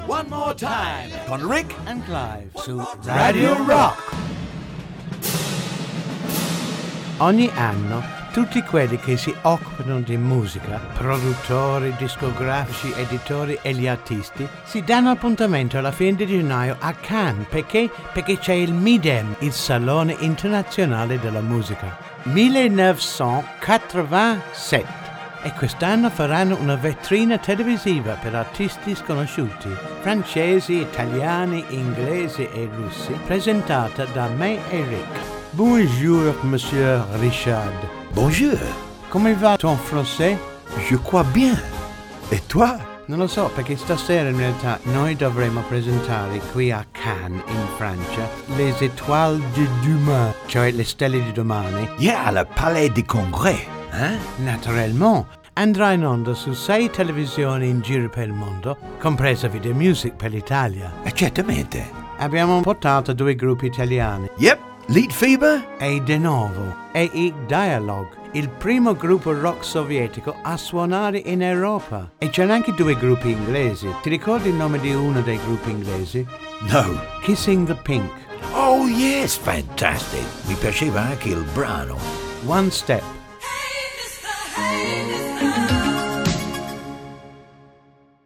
Voci: Rick Hutton, Clive Griffiths.